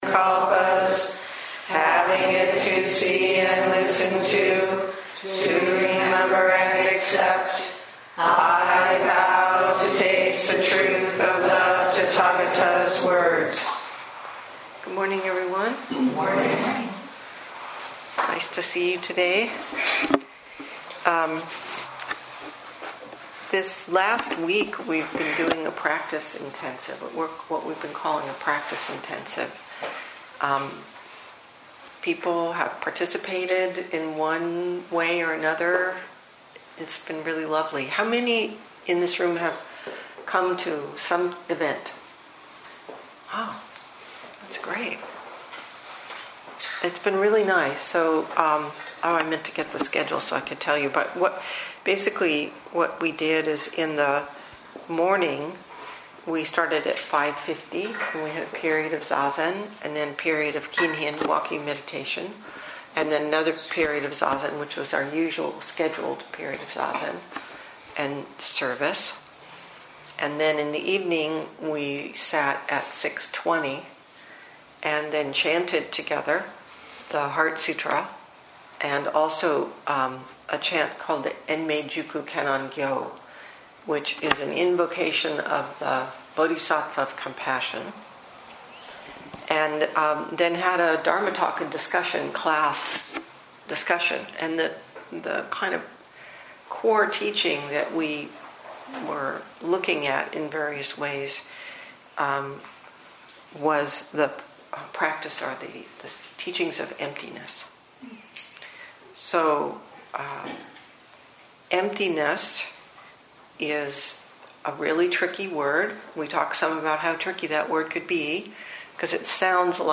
2019 in Dharma Talks